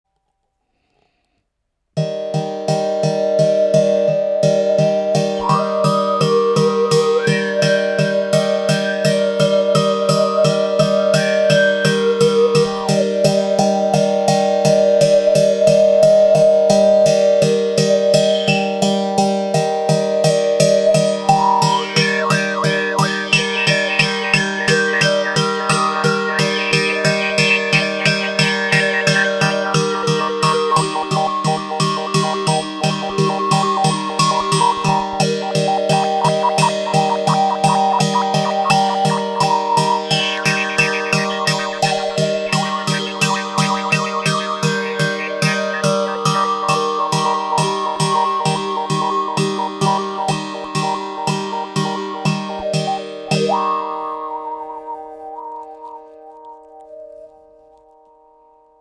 ARC EN BOUCHE 2 CORDES avec cuillère harmonique
Les sons proposés ici sont réalisés sans effet.
La baguette sera utilisée pour percuter les cordes, l’une, l’autre ou Les 2.